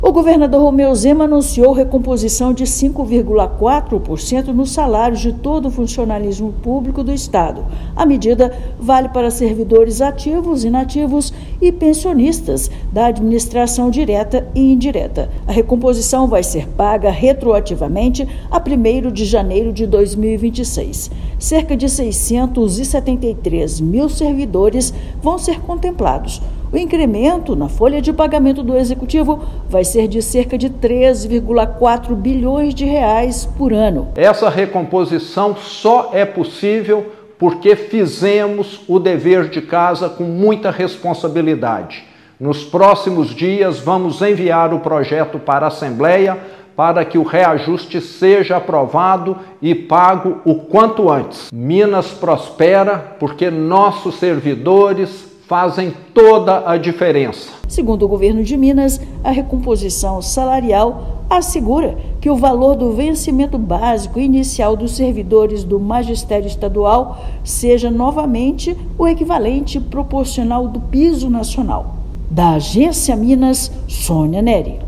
[RÁDIO] Governo de Minas anuncia recomposição salarial de 5,4% para todo o funcionalismo público do Estado
Projeto de Lei sobre a medida será enviado à Assembleia Legislativa de Minas Gerais nas próximas semanas. Ouça matéria de rádio.